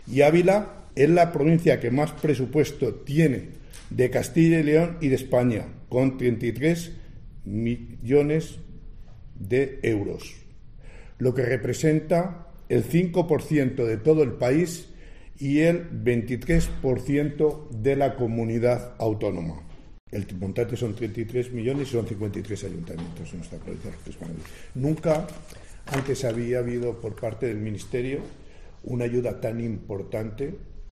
Para el senador Jesús Caro “nunca antes ha llegado una ayuda así”.